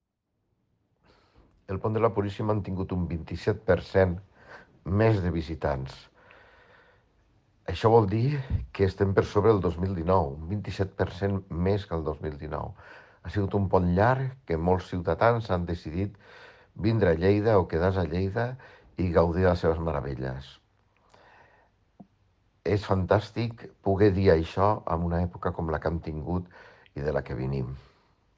Tall de veu del tinent d'alcalde Paco Cerdà sobre el balanç a Lleida del pont festiu de desembre
tall-de-veu-del-tinent-dalcalde-paco-cerda-sobre-el-balanc-a-lleida-del-pont-festiu-de-desembre